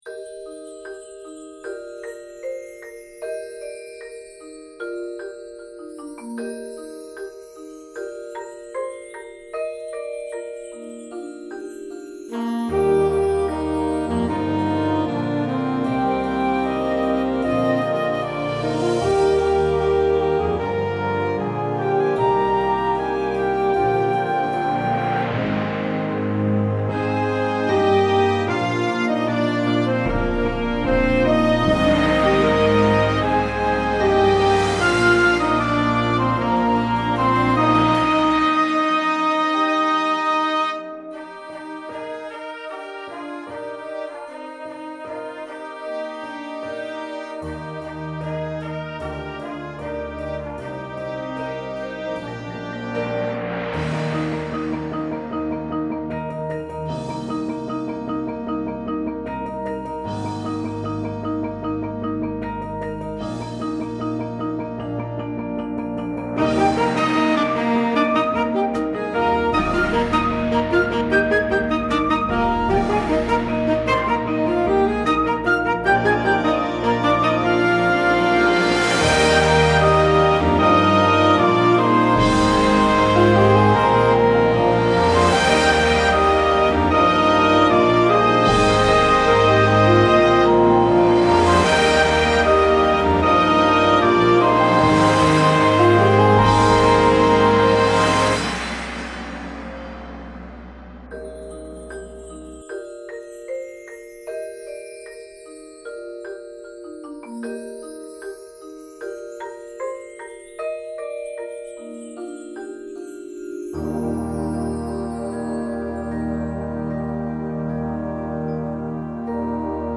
• Flute
• Clarinet 1, 2
• Alto Saxophone
• Trumpet 1, 2
• Horn in F
• Trombone 1, 2
• Tuba
• Snare Drum
• Bass Drums
• Front Ensemble